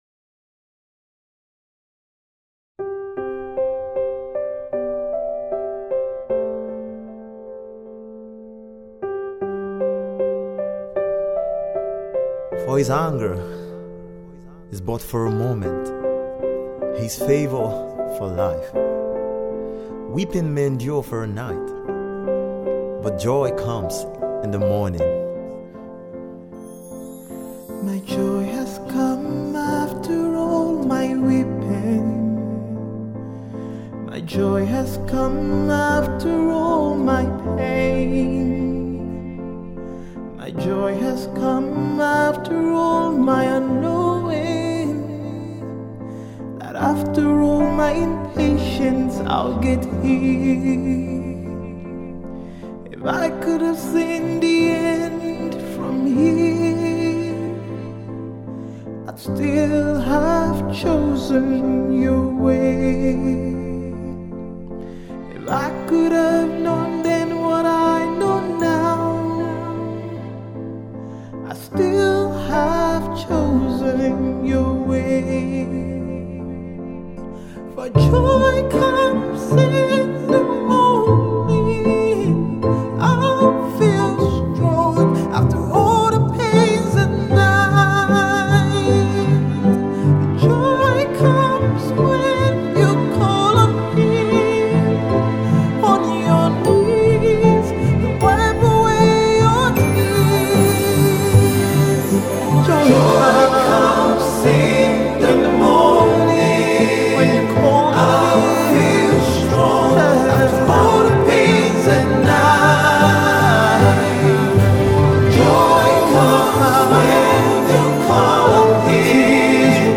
soulful new track